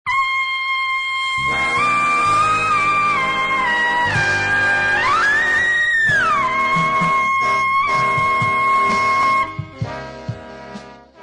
Trumpet Sounds of My Students: